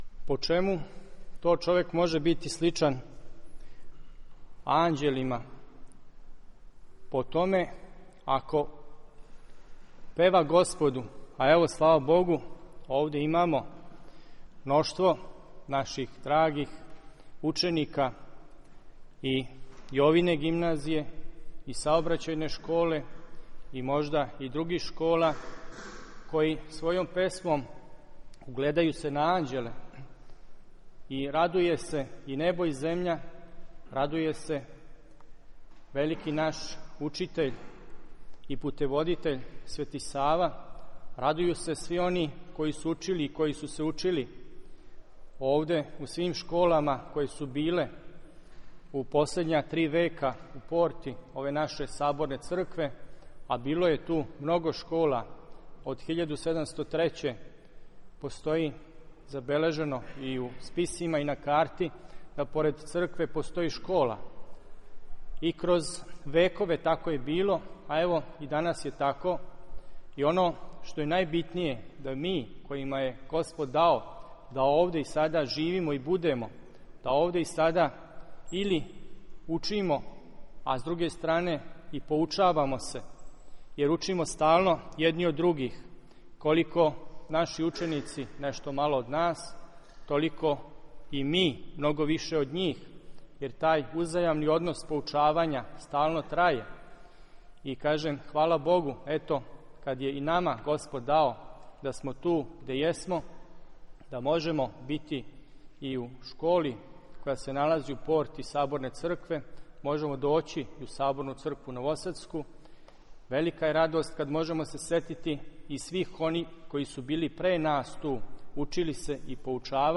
У четвртак, 22. септембра 2011. године, у Саборној цркви у Новом Саду, служен је парастос оснивачима, ктиторима, професорима, ученицима и свима онима који су учили и који су се учили у свим школама које су постојале у последња три века у порти Саборне цркве новосадске.
• Беседа презвитера